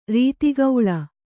♪ rīti gauḷa